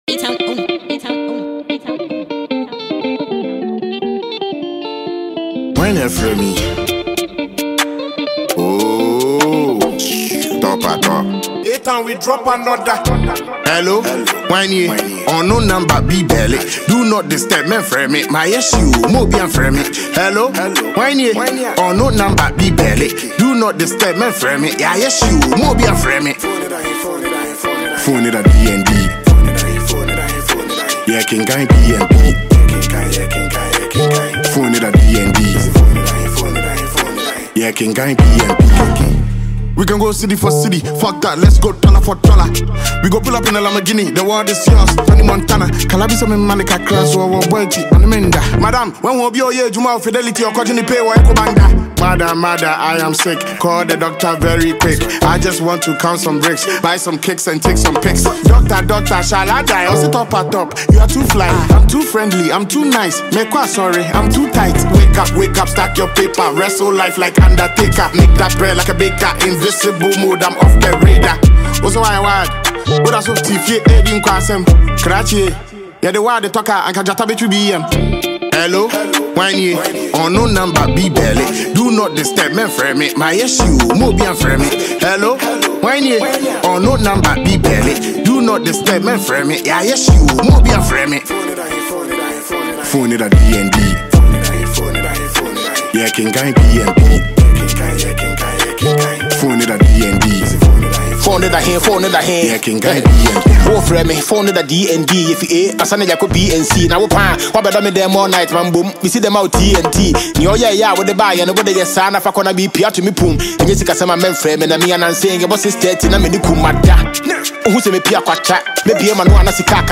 is a smooth yet energetic rap anthem
both rappers deliver top-tier verses and catchy flows